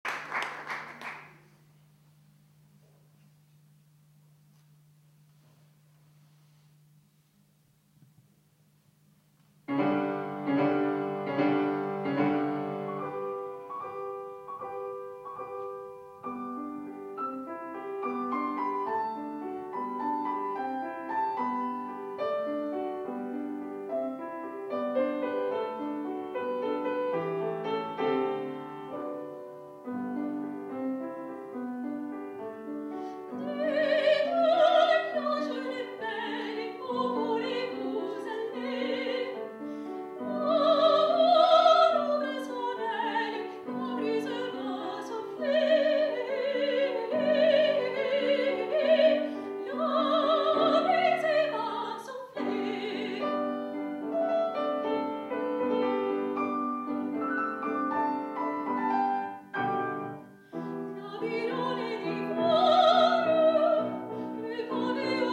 Concerto "Sulle ali del canto"